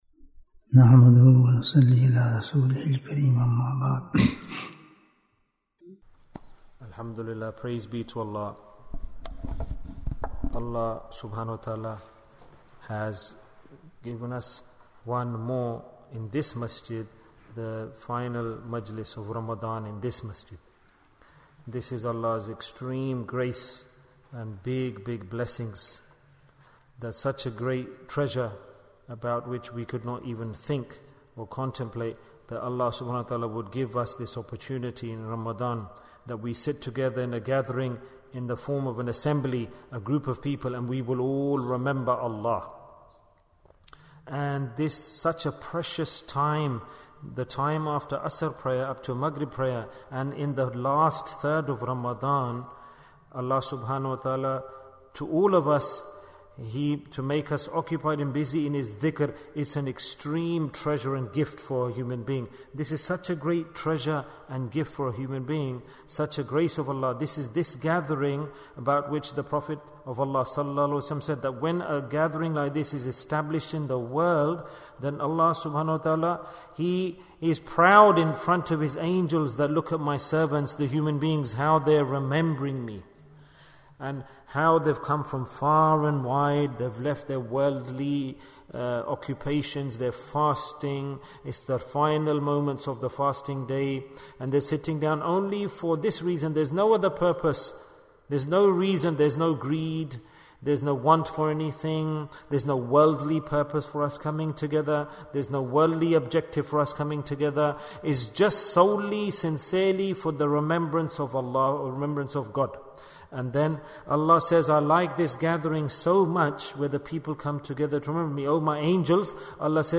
Why Do We Do Dhikr? Bayan, 32 minutes18th April, 2023